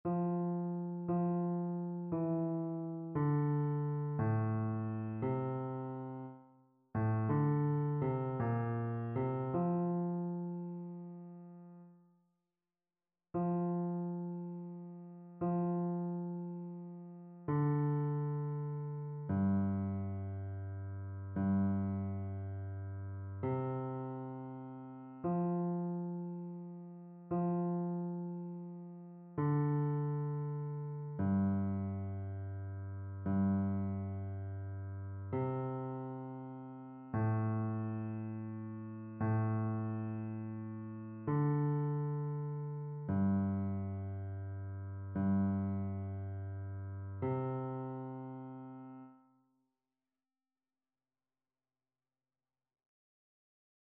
Basse
annee-a-temps-pascal-7e-dimanche-psaume-26-basse.mp3